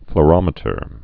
(fl-rŏmĭ-tər, flô-, flō-)